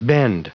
Prononciation du mot bend en anglais (fichier audio)
Prononciation du mot : bend